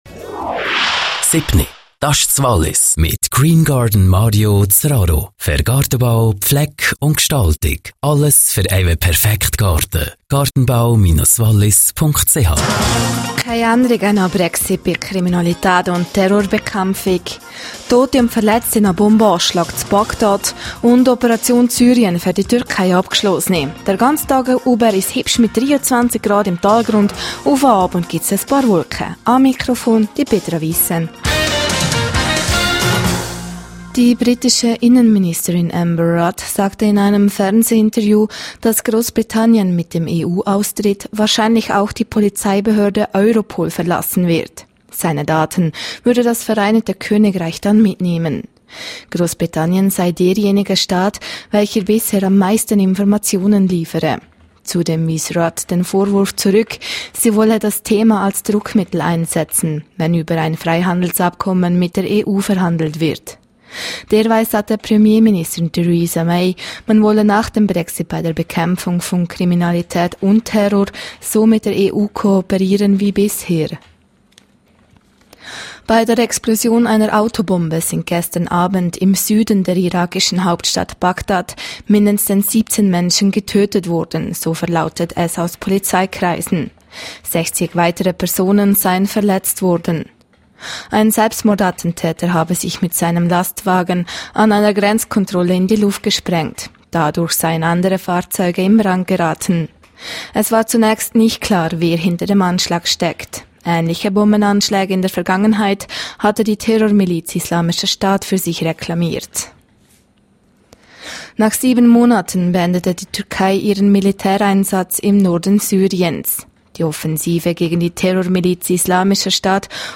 07:00 Uhr Nachrichten (3.9MB)